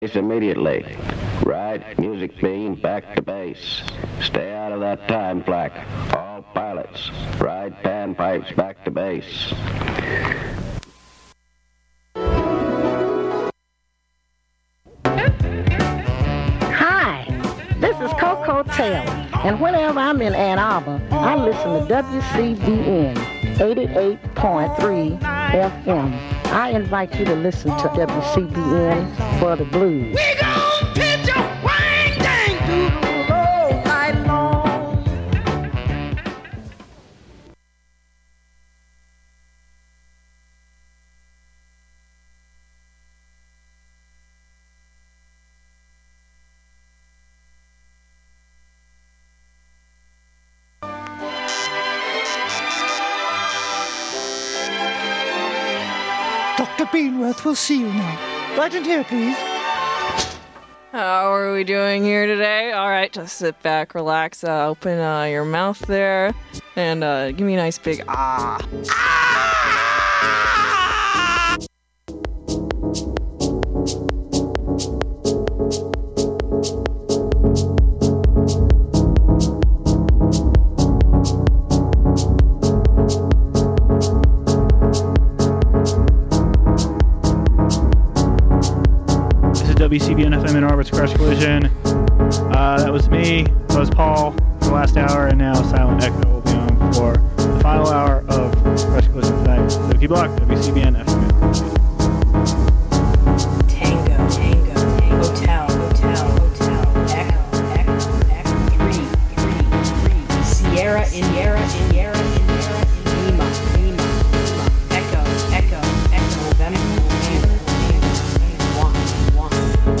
LIVE DJ SET!!!!